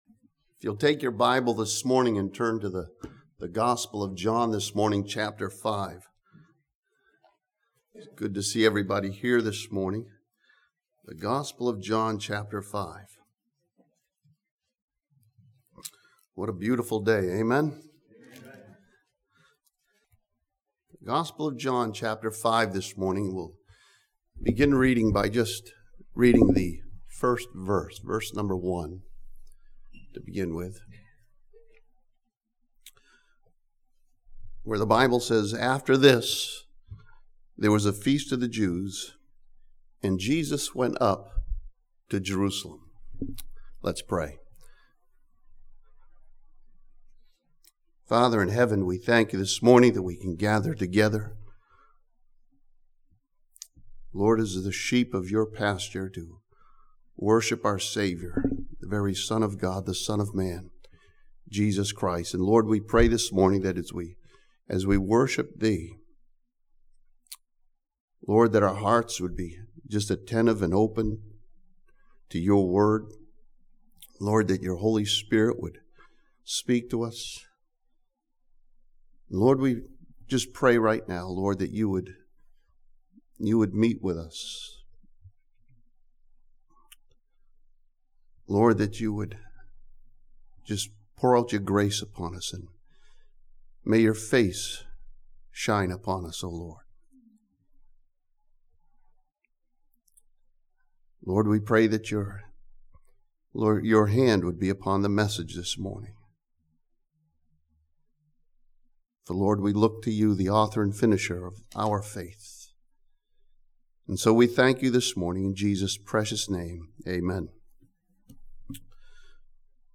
This sermon from John chapter 5 uses a miracle of Jesus as a picture of the church and its relationship to the Savior.